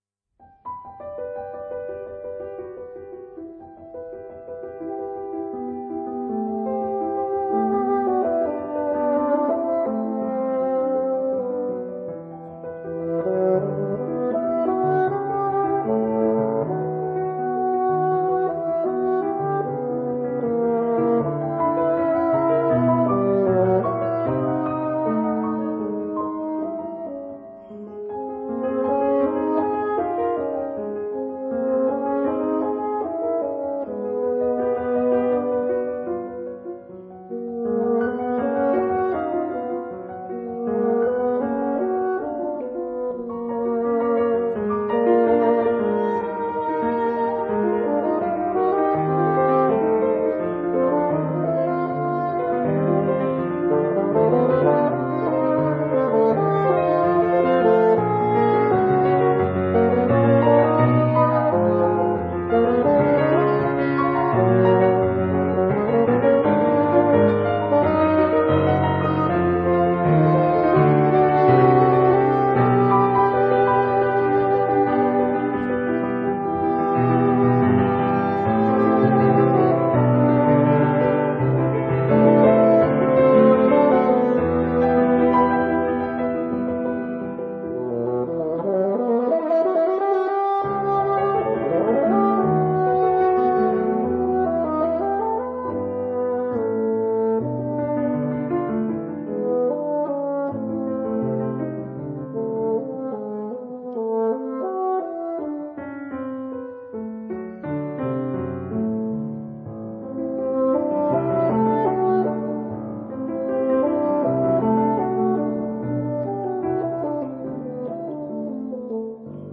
特別是，音色上的控制，巴黎音樂院的木管傳統，的確是強。